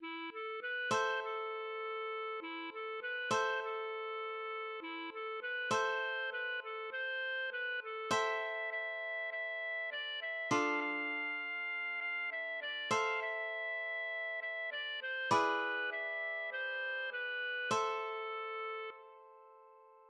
\version "2.18.2" \paper { print-page-number = ##f } \header{ dedication = "🏰??" title = "Ritter Sigman" subtitle = "Ein trauriges Lied über den Großfuchs" subsubtitle = "
LilyPond 🏰" } myMusic= { << \chords { \germanChords \set chordChanges=##t \set Staff.midiInstrument="acoustic guitar (nylon)" s4.
\set Staff.midiInstrument="clarinet" e'8 a8 b8 | c8 a8~ a4. e8 a8 b8 | c8 a8~ a4. e8 a8 b8 | c4 b8 a8 c4 b8 a8 | e'4 e4 e4 d8 e8 | f8 f4.~ f8 f8 e8 d8 | f8 e4.~ e8 e8 d8 c8 | b4 e4 c4 b4 a2 r8 \bar "|."